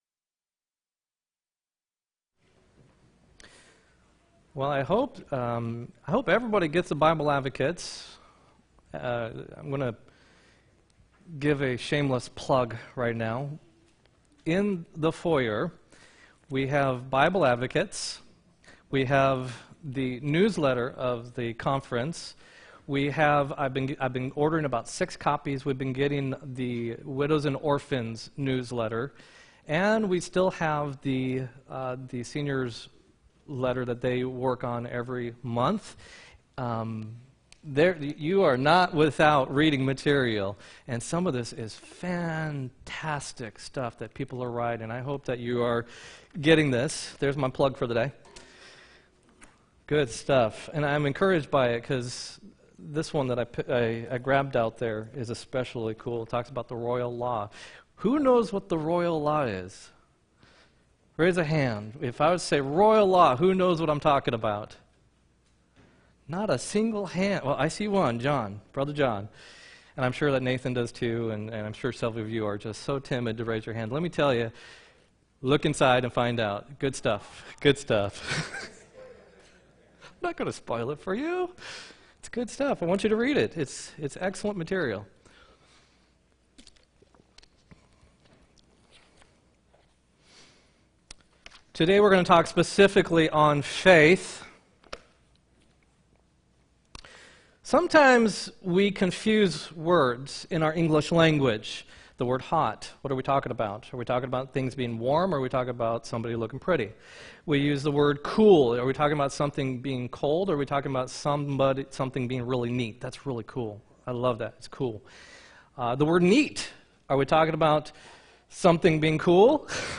7-7-18 sermon